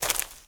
STEPS Leaves, Walk 11.wav